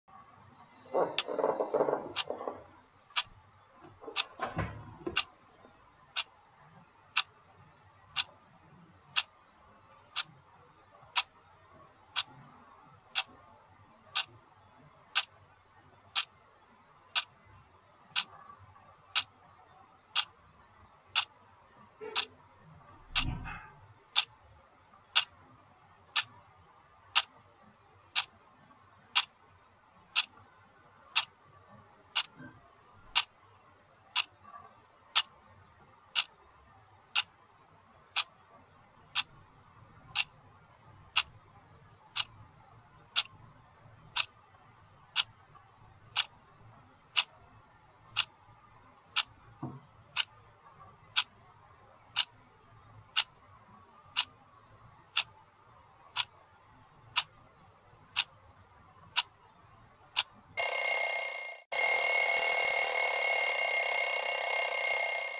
دانلود صدای ثانیه شمار 9 از ساعد نیوز با لینک مستقیم و کیفیت بالا
جلوه های صوتی